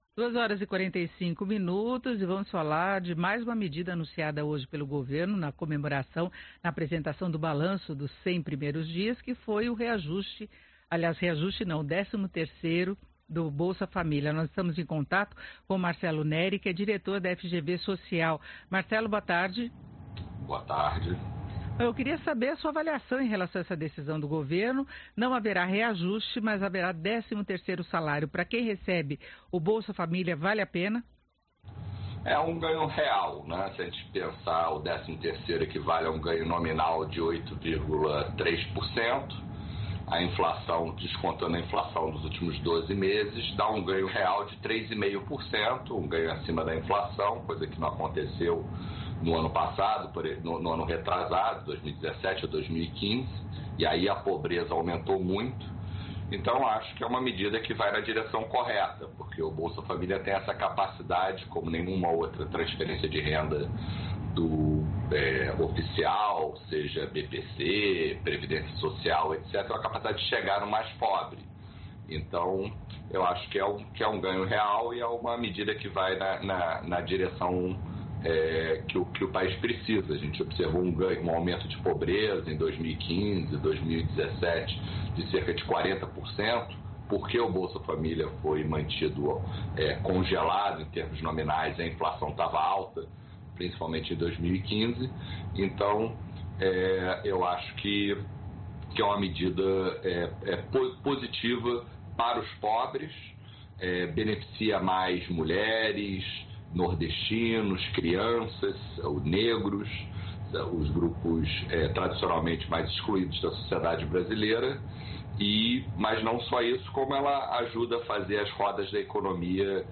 • Rádio